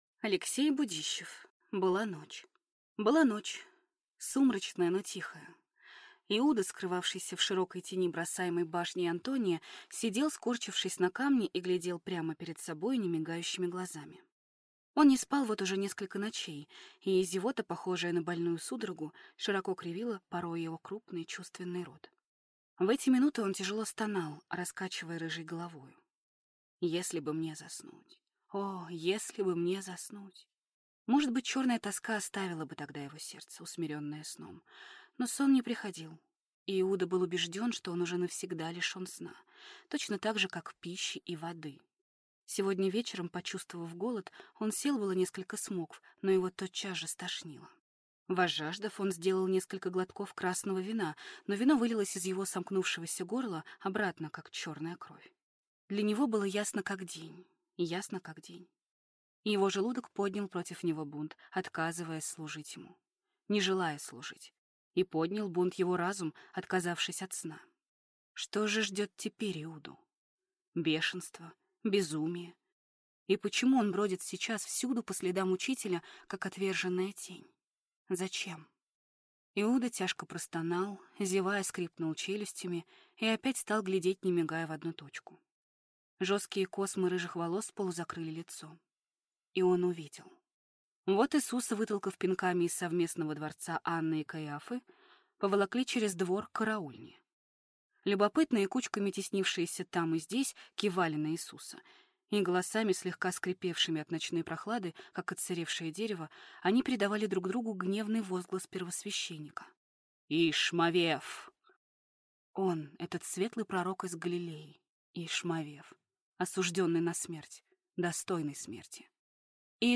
Aудиокнига Была ночь